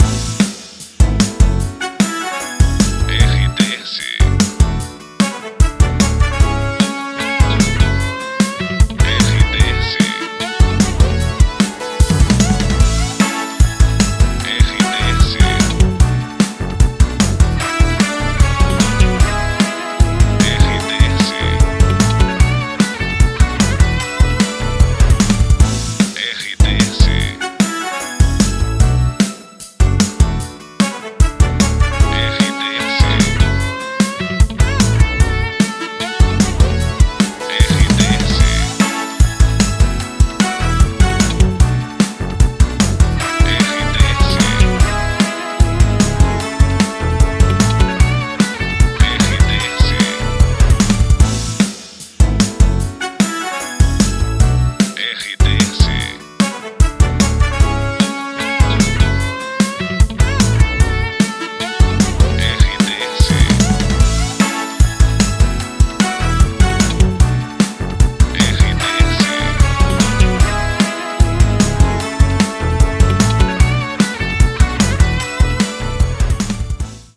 Trilha para locução